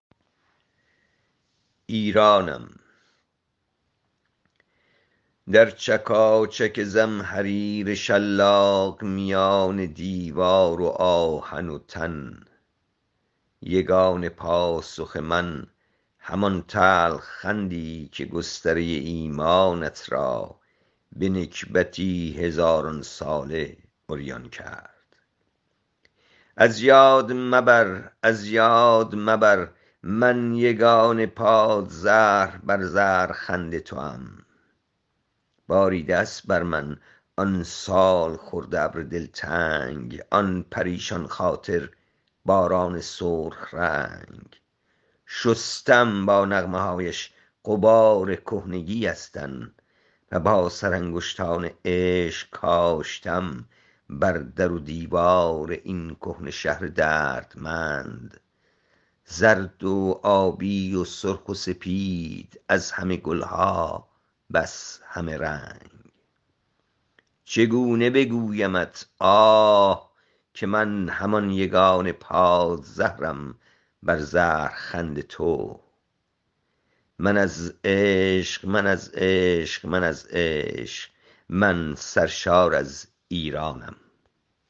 این شعز را با صدای شاعر بشنوید